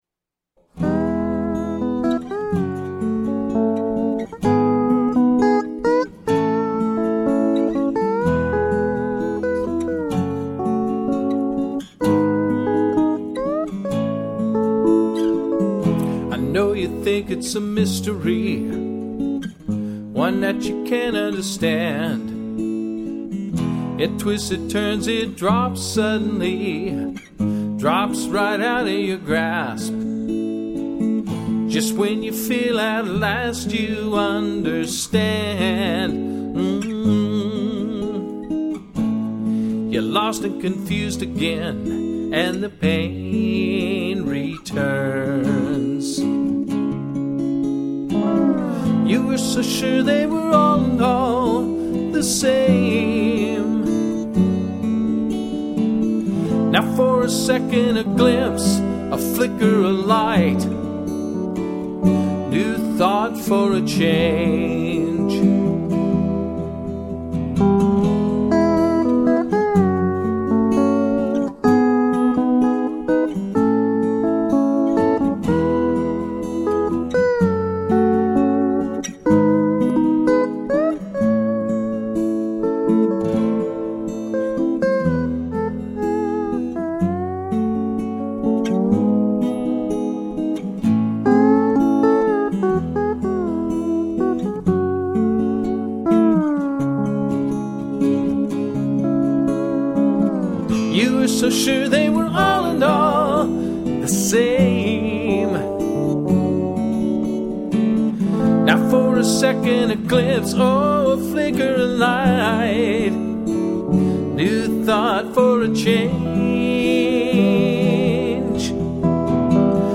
Most of my own C6th playing doesn't sound at all like western swing or jazz. I use the C6th mostly for blues and folk music.
I suppose that it could have been played on E9th, but I wouldn't have thought of it on E9th.
all_in_all.trio.mp3